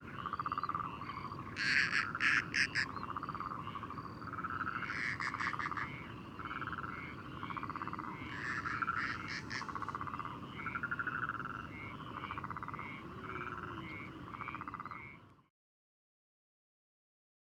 According to Fry et al (1988), the female’s voice is higher-pitched and softer than the male’s. In CD3-03, from the main courtship period in March, a male and female are emerging from their breeding territory at dusk. In this recording, any pitch difference between the two individuals is negligible and I hear no difference in timbre or ‘softness’, so I have left them unsexed.
Song croaks of male and female, emerging from their breeding territory at dusk.
03-Marsh-Owl-Song-Croaks-Of-Male-Female-At-Dusk.wav